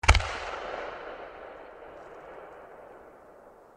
shoot.mp3